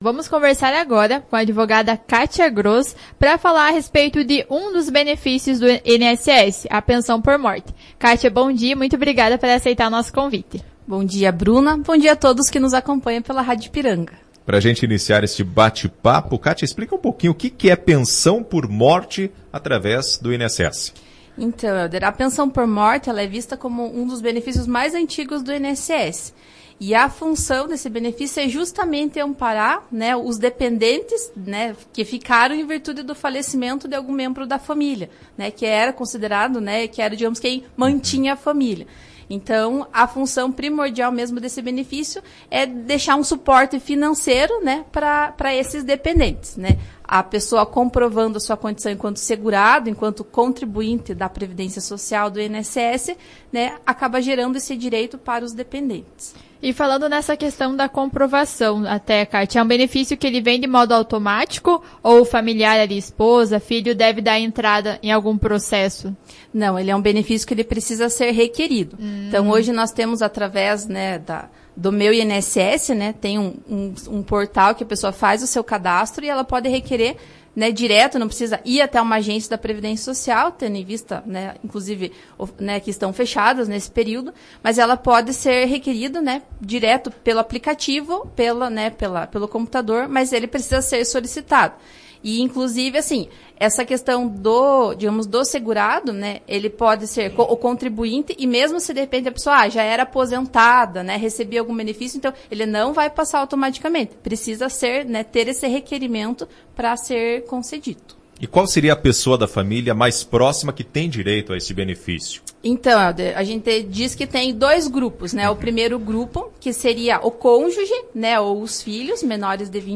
Pessoas que tem direito de receber o benefício, requisitos, carência, quando solicitar a pensão por morte, valor mensal, além de dúvidas enviadas por ouvintes foram esclarecidas durante a entrevista com a profissional.